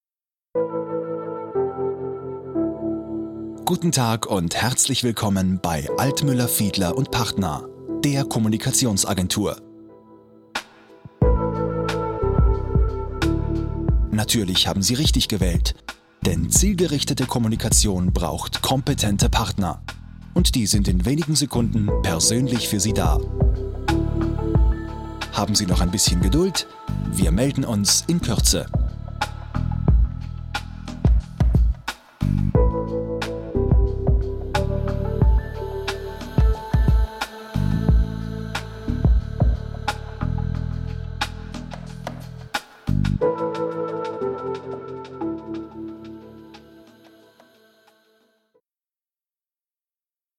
deutscher Sprecher, klar, markant, mittellage, große Bandbreite, variabel
Sprechprobe: Sonstiges (Muttersprache):
german voice over artist